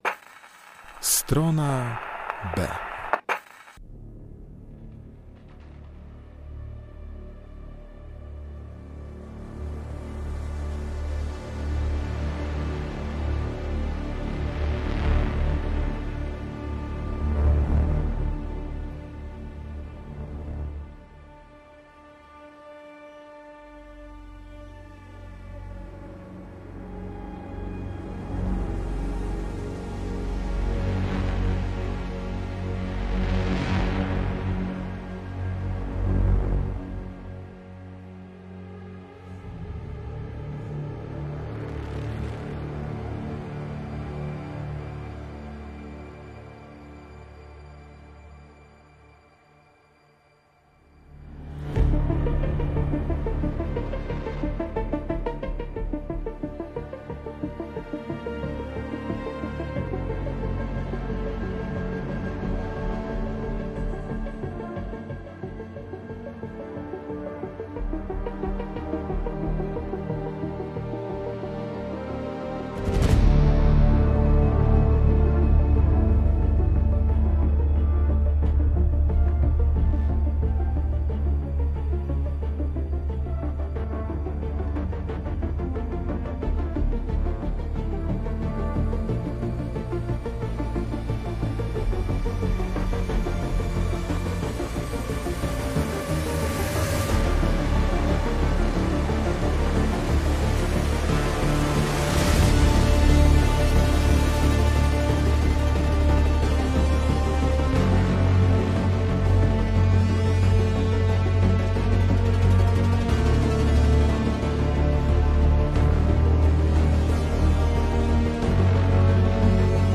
Elektronika